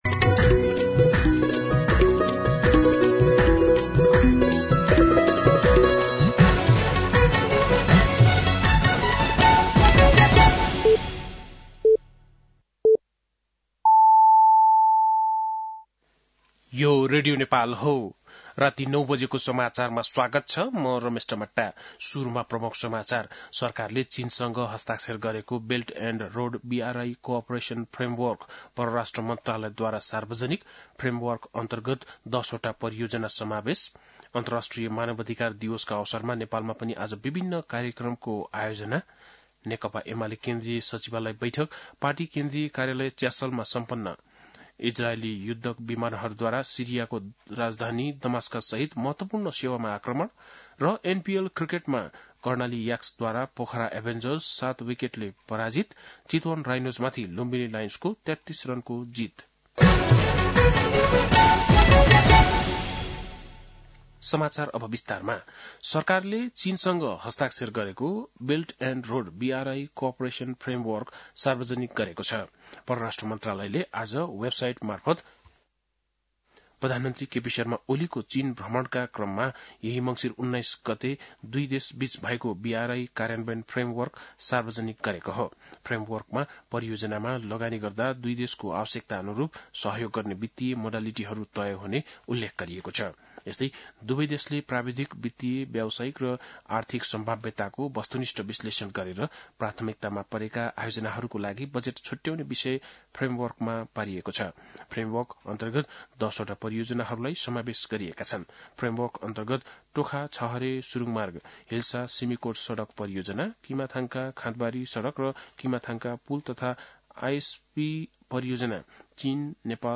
बेलुकी ९ बजेको नेपाली समाचार : २६ मंसिर , २०८१
9-PM-Nepali-News-8-25.mp3